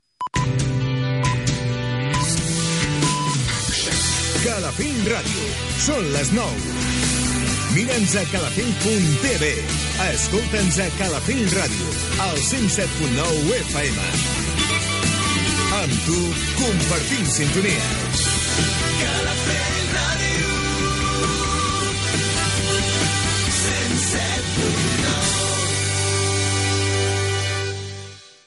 Senyals horaris, les 9 del matí i indicatiu de l'emissora i esment de la freqüència, als 107.9 MHz